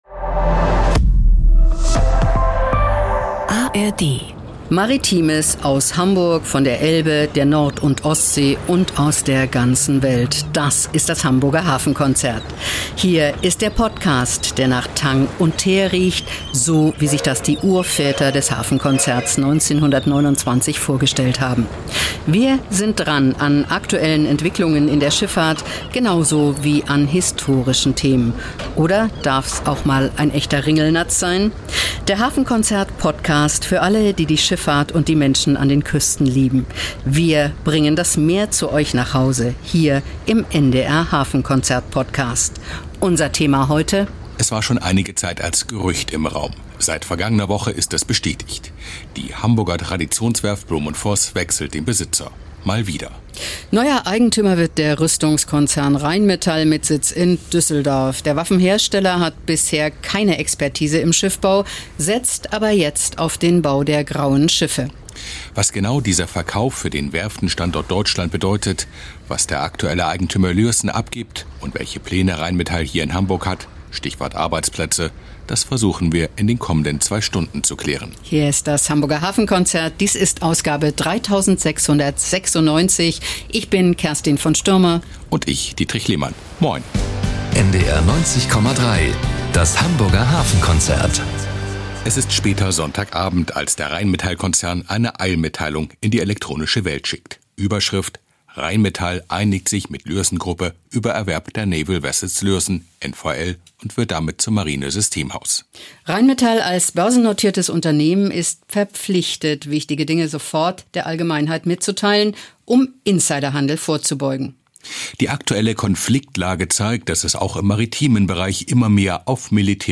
sprechen mit Politikerinnen und Politikern, Werftarbeitern, Gewerkschaftern, Rüstungsexperten – und werfen auch einen Blick zurück auf fast 150 Jahre Werftgeschichte an der Elbe.